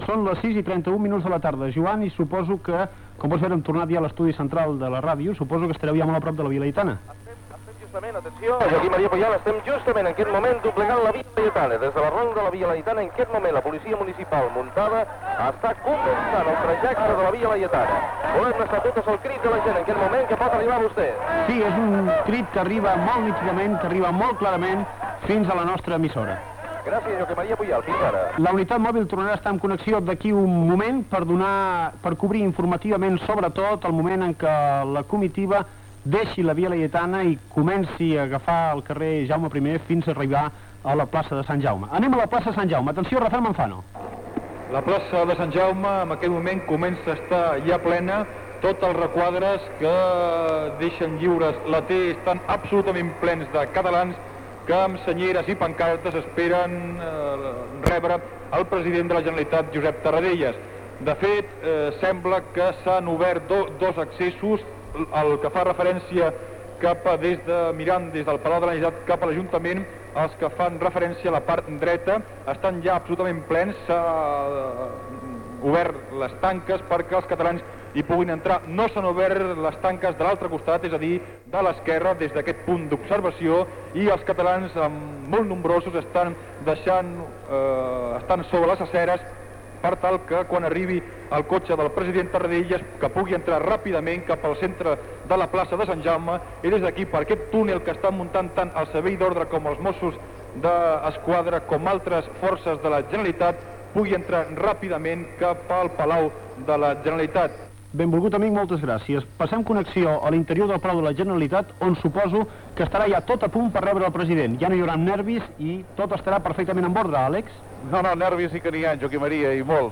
Transmissió del retorn de l'exili del president de la Generalitat Josep Tarradellas a la ciutat de Barcelona. Connexions amb la Via Laitena, l'exterior de la Plaça Sant Jaume i l'interior del Palau de la Generalitat.